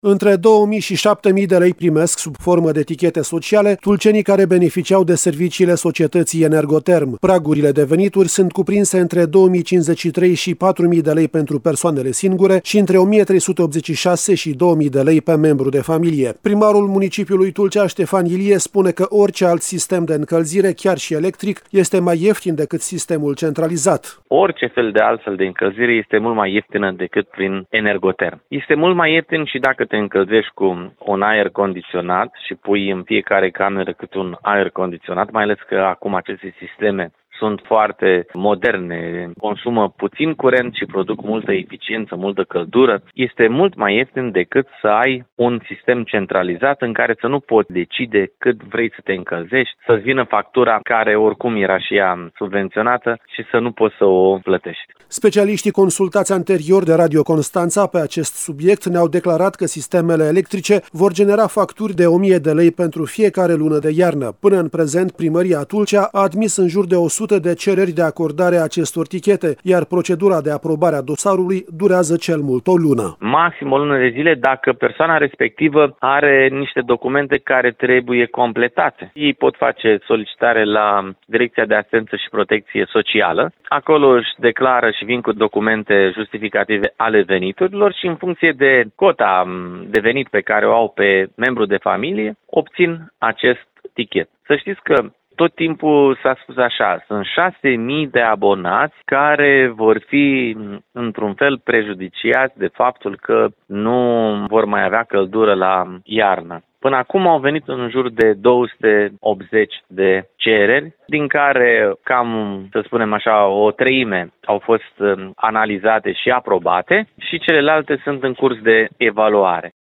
Primarul municipiului Tulcea, Ștefan Ilie, spune că orice alt sistem de încălzire, chiar și electric, este mai ieftin decât sistemul centralizat.